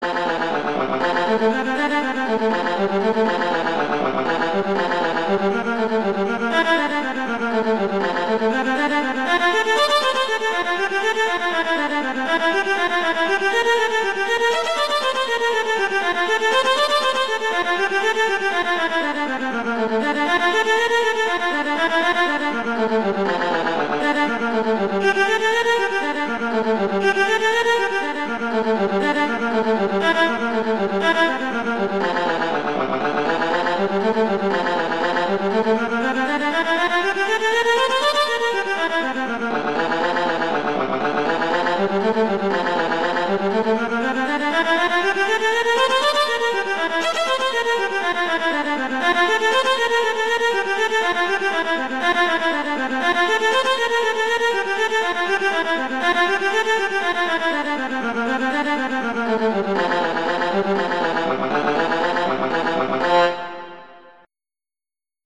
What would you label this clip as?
classical, instructional